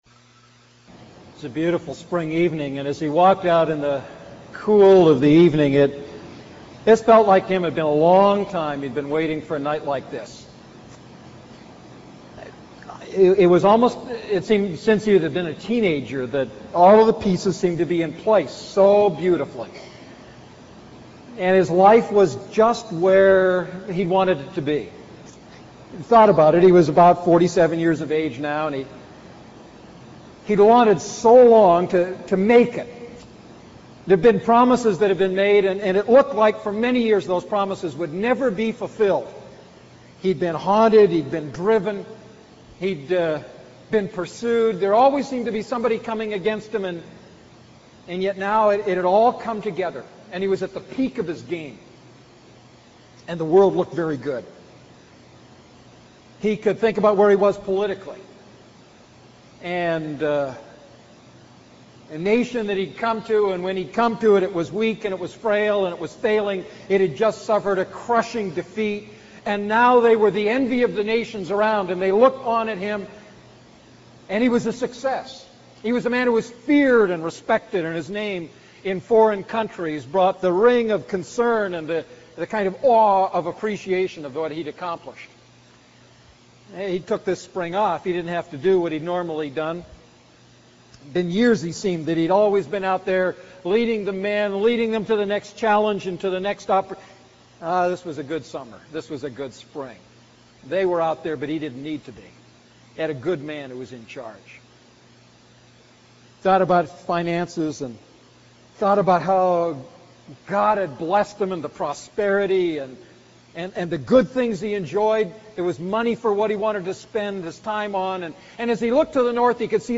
A message from the series "The Ten Commandments."